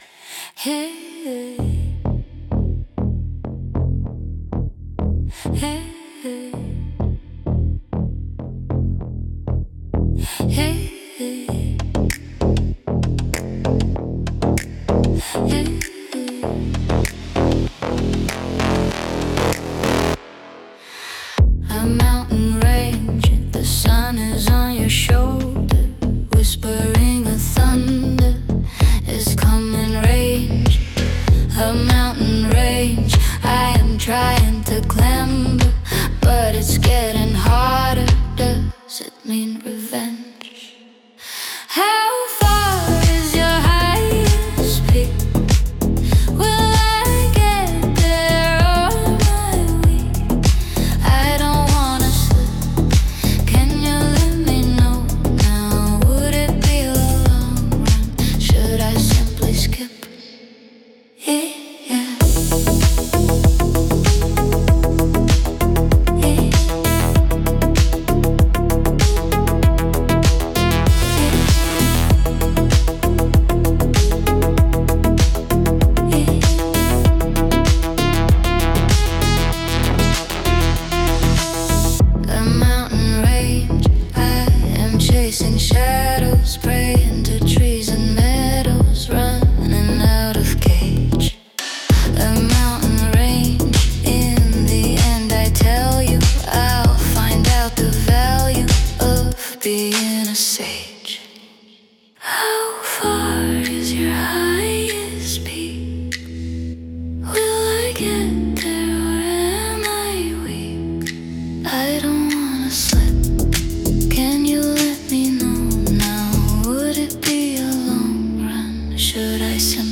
Пример 7: Классический драм-энд-бейс
драм-энд-бейс, быстрый темп, ломаный ритм, быстрые ударные, плотная ритм-сетка, энергичный грув, чёткая перкуссия